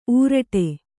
♪ ūraṭe